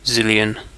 Ääntäminen
Synonyymit armadillion quadrillion Ääntäminen UK Tuntematon aksentti: IPA : /ˈzɪljən/ Haettu sana löytyi näillä lähdekielillä: englanti Käännöksiä ei löytynyt valitulle kohdekielelle.